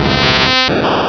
Fichier:Cri 0011 DP.ogg — Poképédia
Cri_0011_DP.ogg